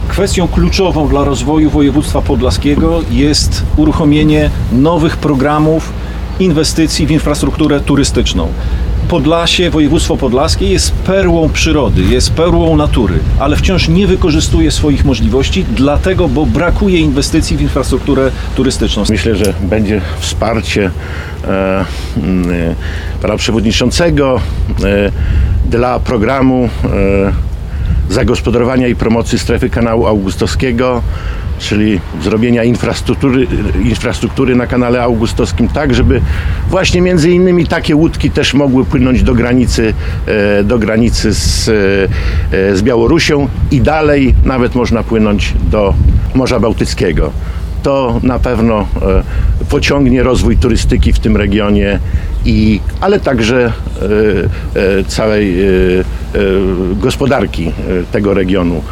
Polityk spotkał się z przedsiębiorcami, zwiedzał jedną z miejscowych firm, produkujących łodzie i rozmawiał z dziennikarzami. Mówił o roli pracodawców, potrzebie wspierania przedsiębiorców oraz stabilności prawa.